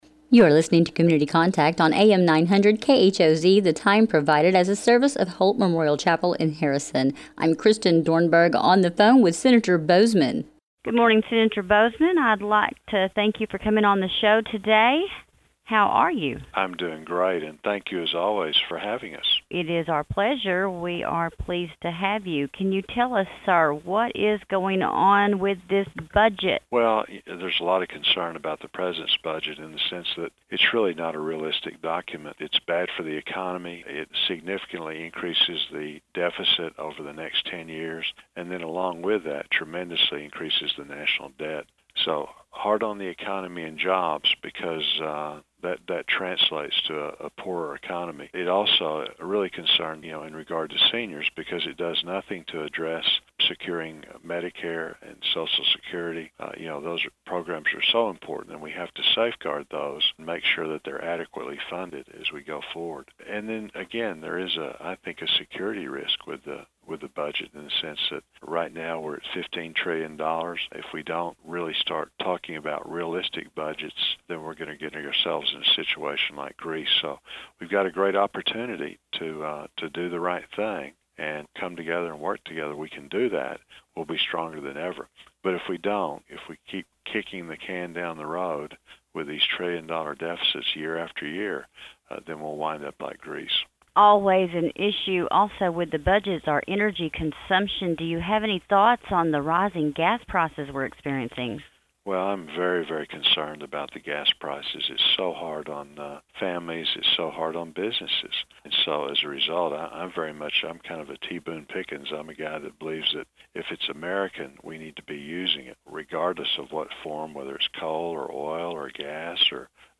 ICYMI: Conversation with KHOZ
Senator Boozman discusses the budget, gas prices, HHS contraceptive mandate and Second Amendment Rights in this discussion with Harrison radio KHOZ.